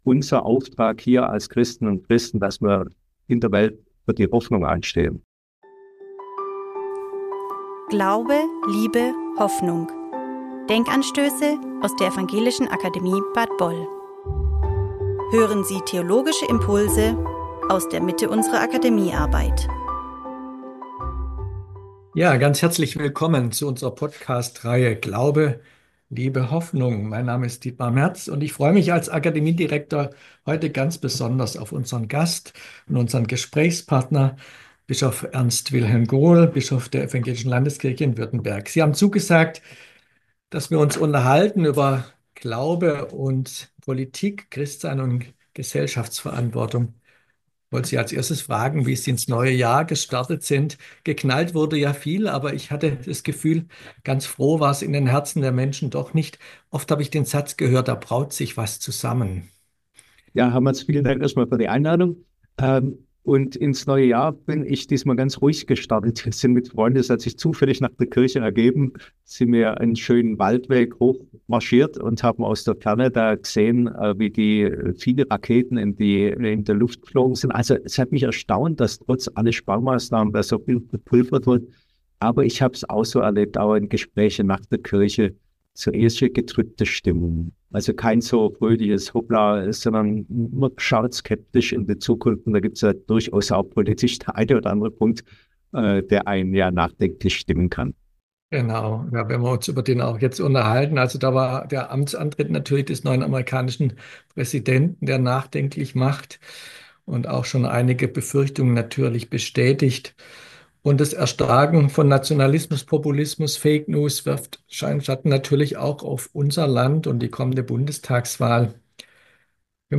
Ein Gespräch über Glaube und Politik, über Christsein und gesellschaftliche Verantwortung.